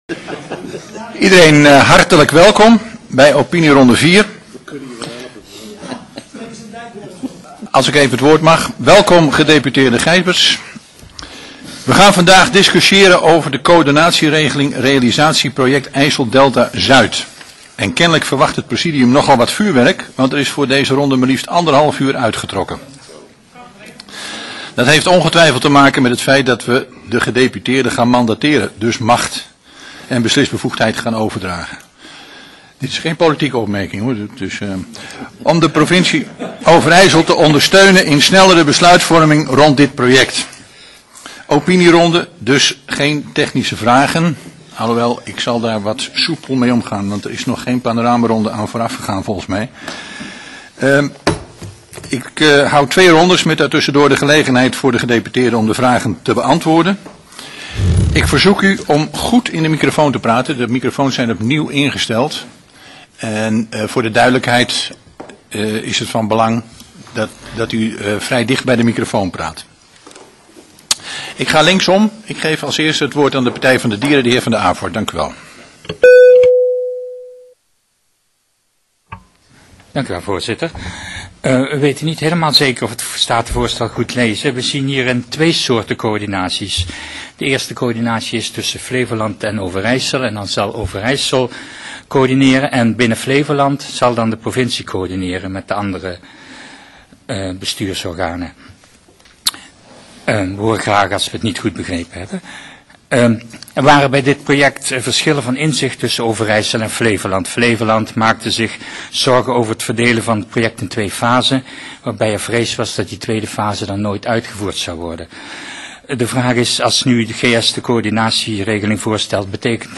Download de volledige audio van deze vergadering
Locatie: Statenzaal